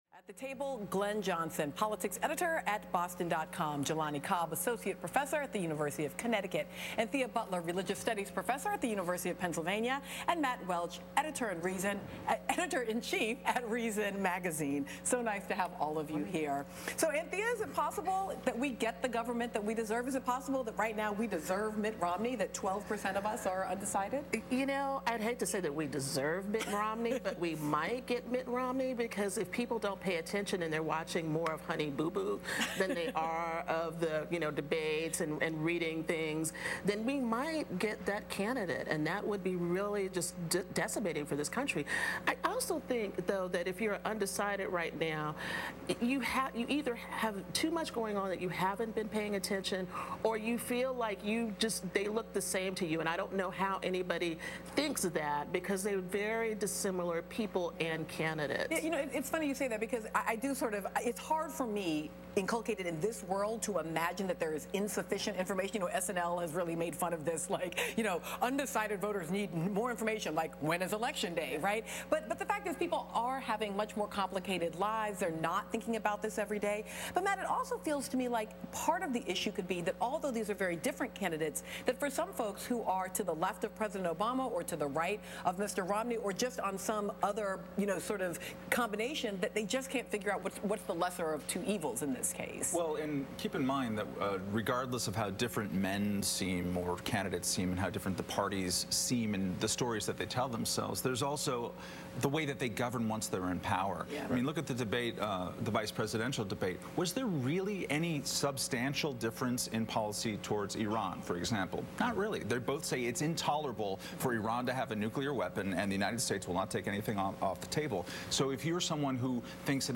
Matt Welch Joins a Panel on MSNBC to Discuss What's Driving 2012's Election